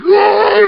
zombie_voice_idle4.mp3